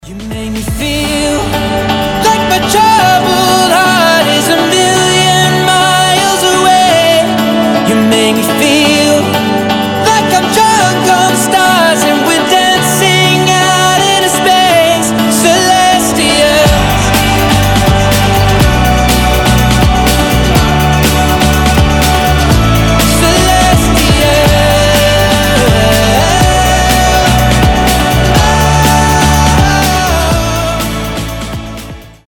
• Качество: 320, Stereo
вдохновляющие